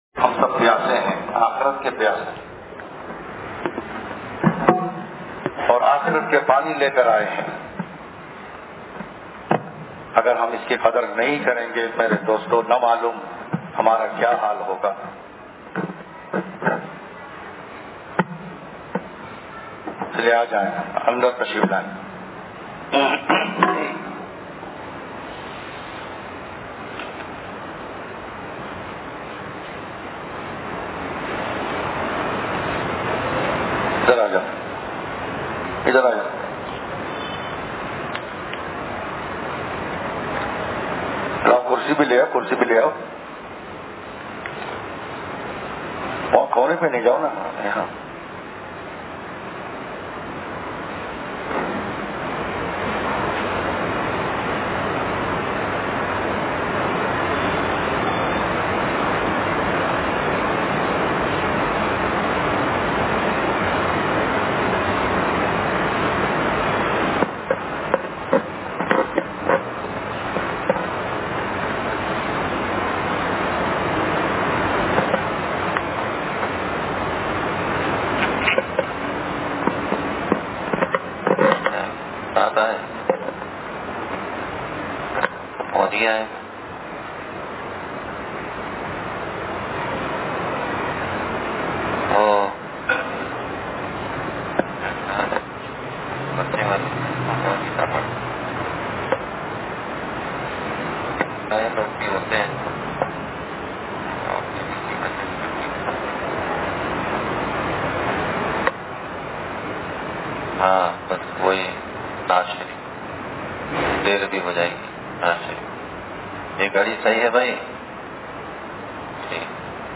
بیان – انصاریہ مسجد لانڈھی